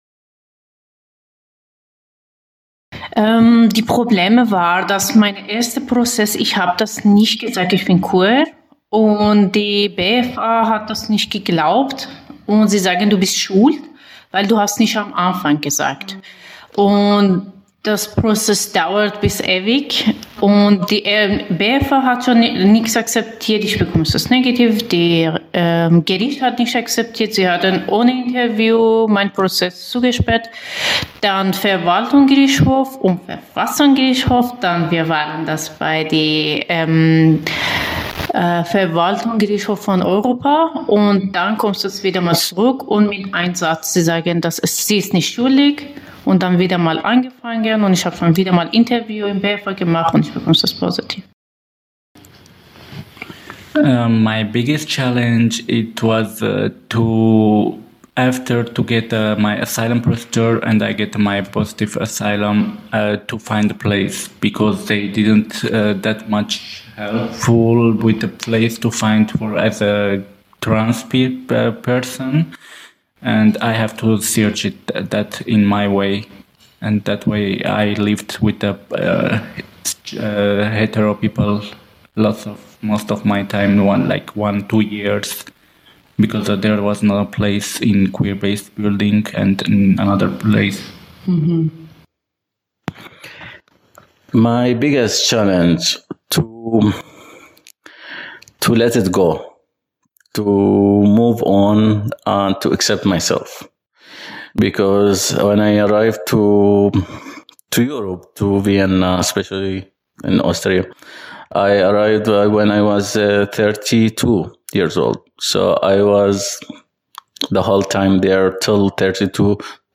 Oral History: Die Geschichte queerer Migration und Flucht ist geprägt von dem Streben nach Freiheit und Selbstbestimmung.